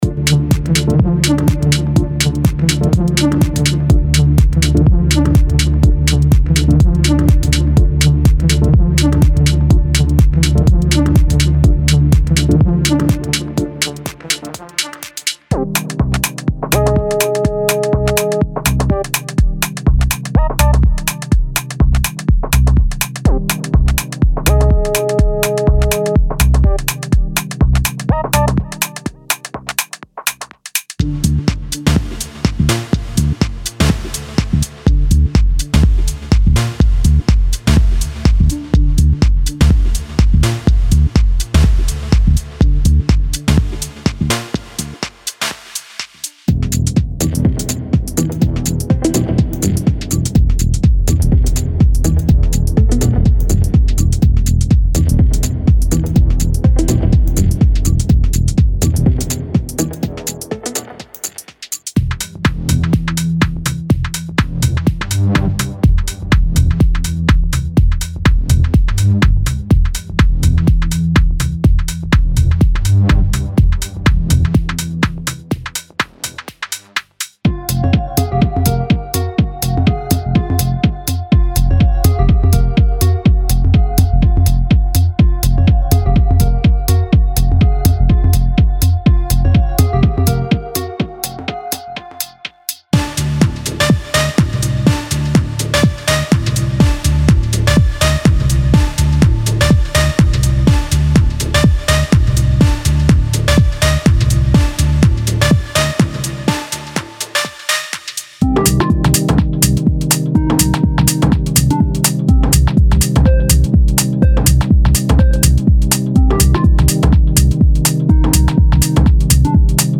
Genre:Minimal Techno
デモサウンドはコチラ↓